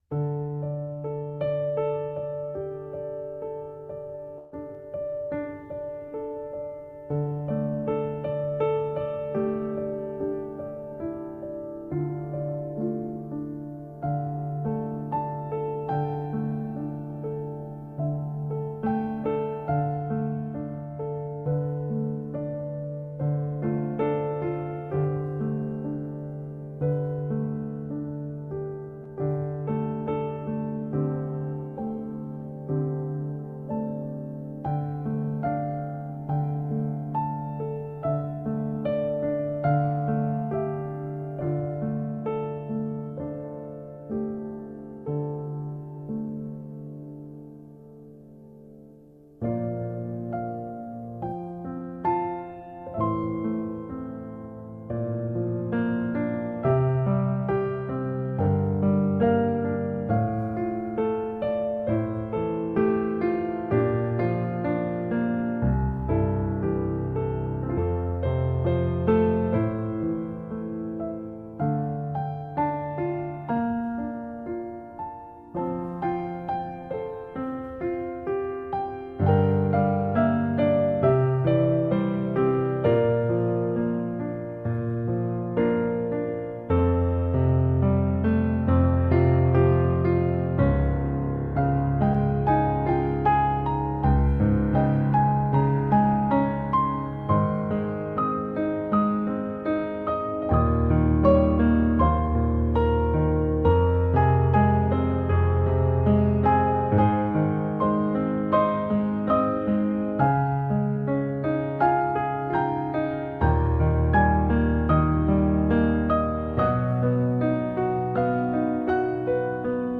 Per sentire come dalla malinconia, attraverso una tappa nei ricordi, si passa alla speranza, ascolta questo brano.